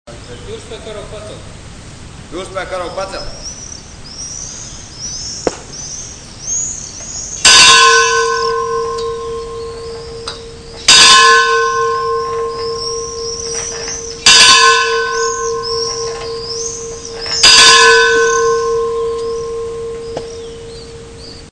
morning ceremony